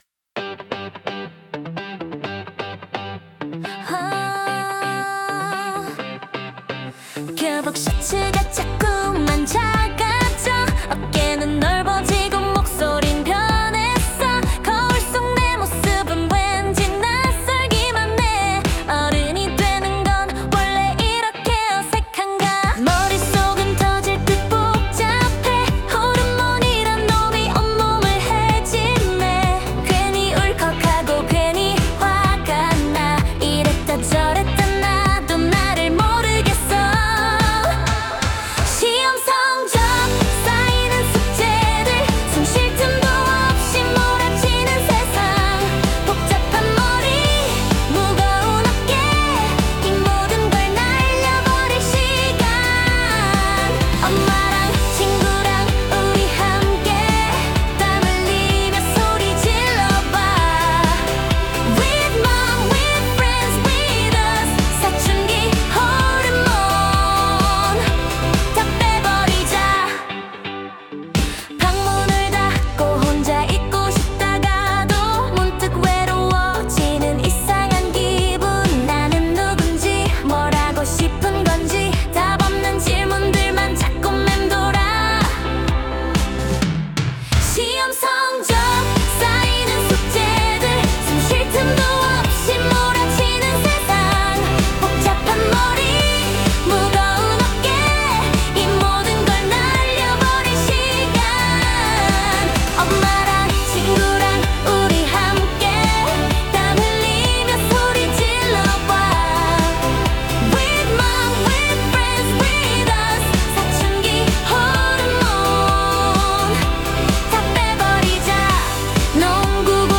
Suno로 만든GET(GrowingExerciseTogether)응원송
얼마 전, SUNO라는 AI로 내 생각을 가사에 담아 '공부방 운동송'을 만들었다.